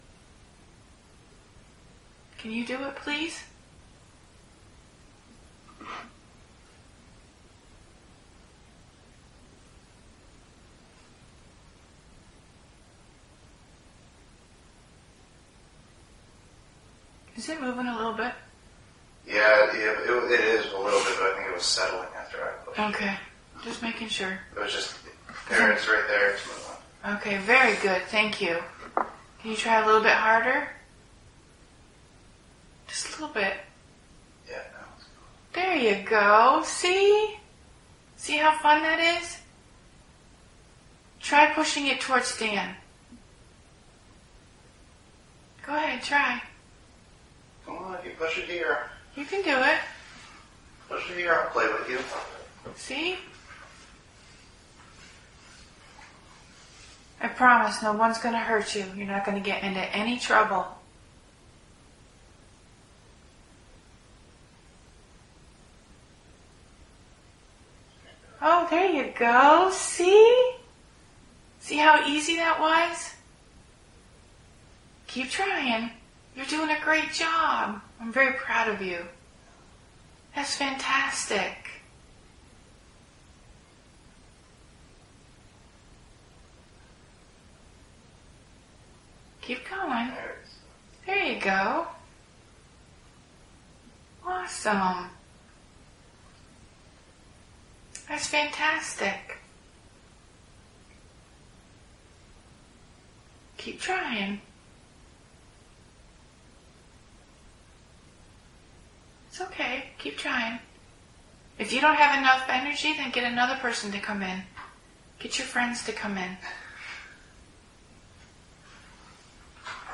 Dining Hall – 1st Floor
Obviously you cannot see it on the recorder but you can hear us refer to it at multiple points beginning at :27 when the balloon moved for the first time.